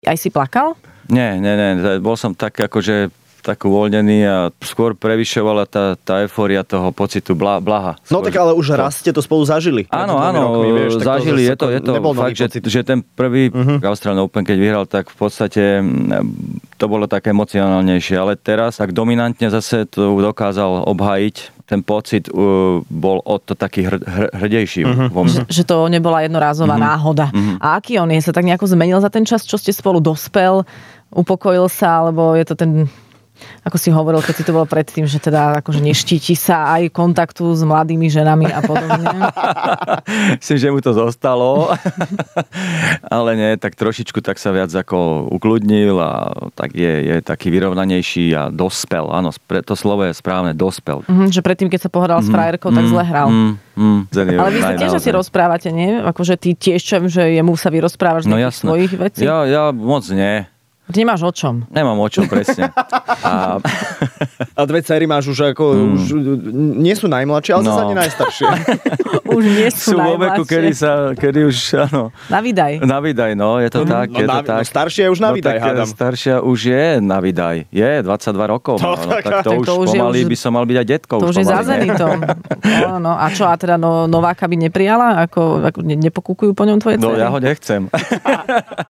Hosťom v Rannej šou bol tréner svetového tenistu Novaka Djokoviča!